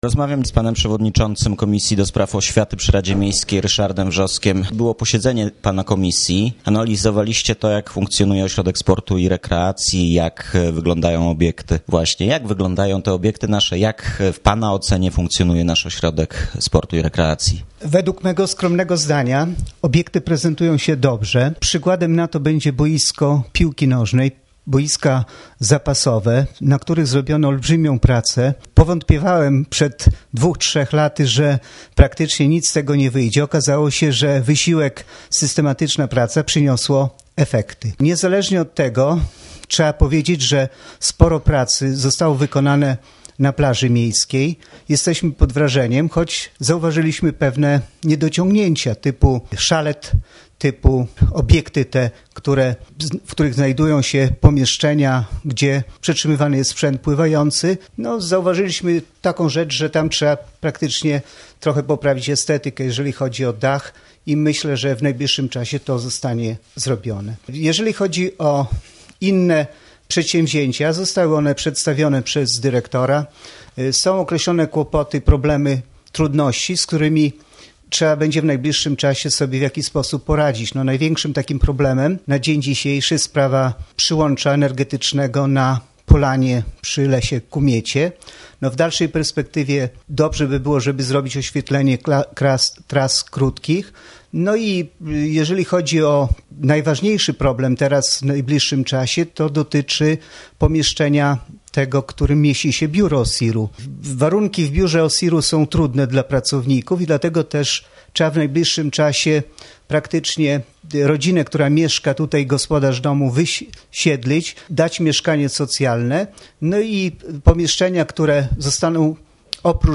rozmowa z Ryszardem Wrzoskiem, przewodniczącym komisji